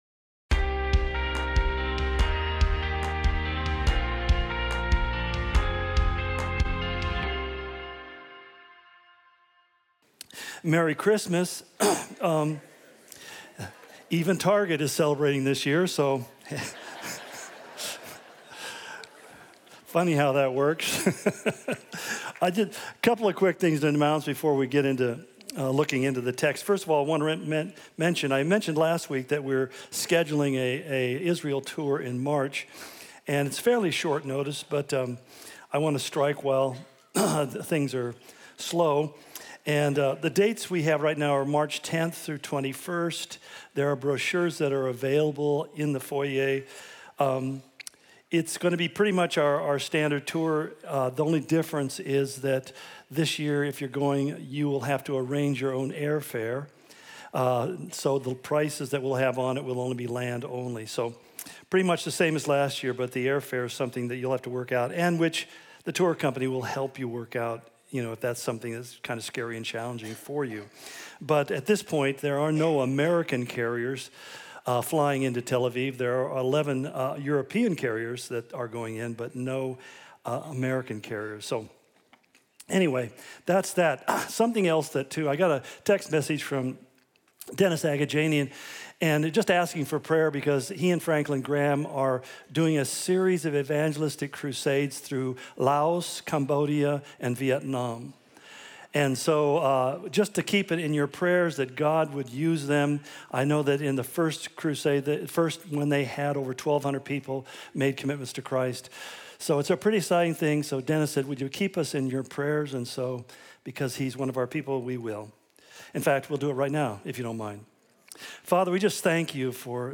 Calvary Spokane Sermon Of The Week podcast